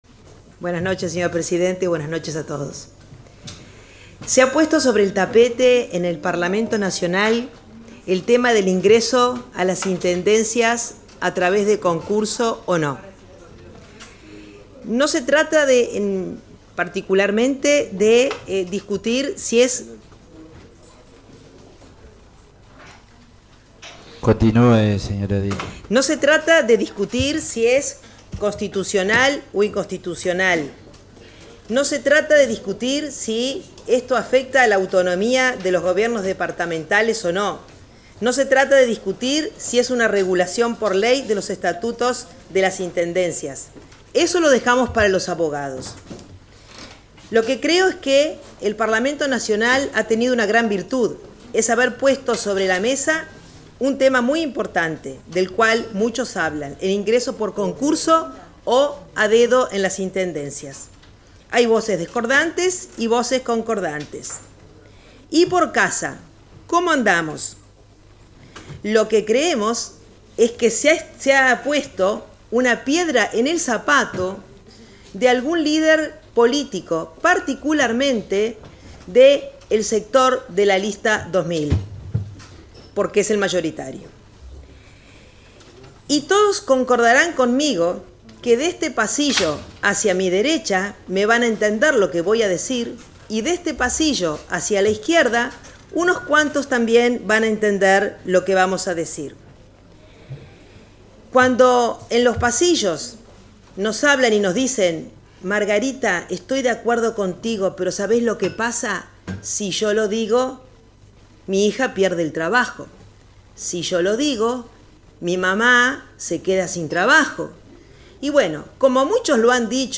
2-Sra. Edil Margarita García: